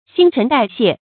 注音：ㄒㄧㄣ ㄔㄣˊ ㄉㄞˋ ㄒㄧㄝ ˋ
新陳代謝的讀法